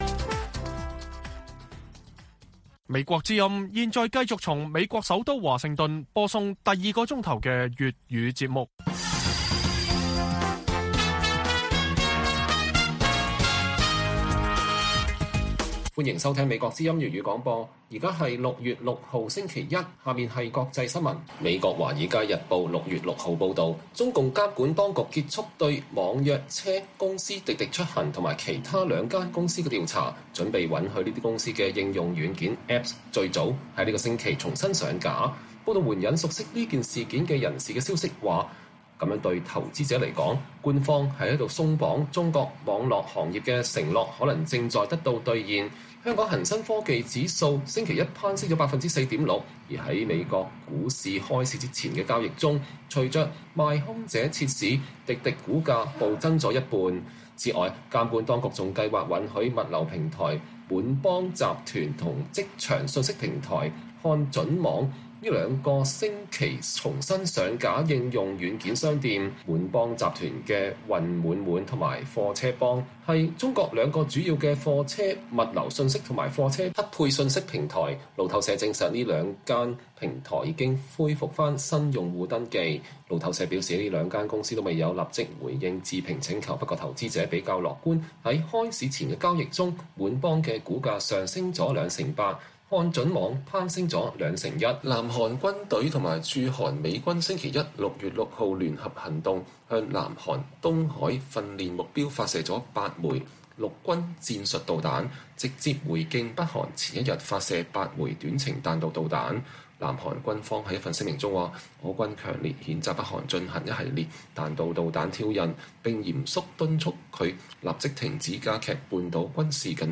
粵語新聞 晚上10-11點：中國網民在審查機器的夾縫間紀念六四